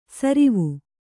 ♪ sarivu